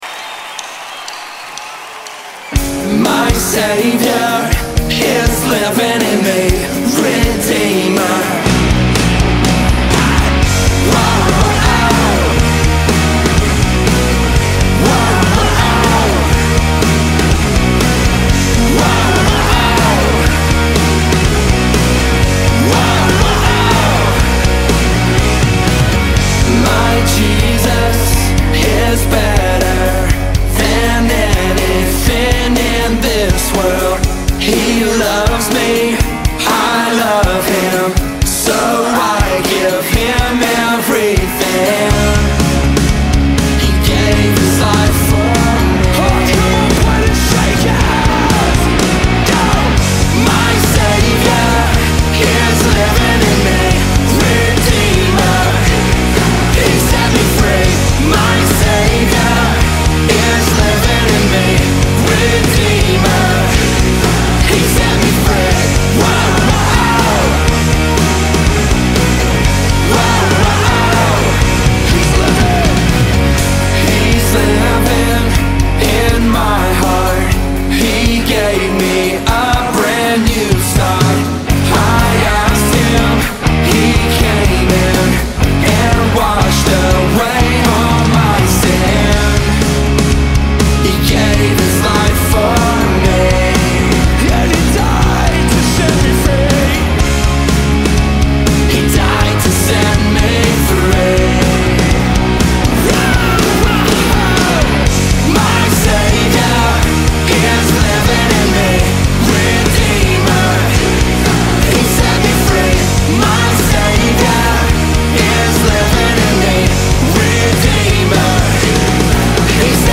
56 просмотров 21 прослушиваний 0 скачиваний BPM: 122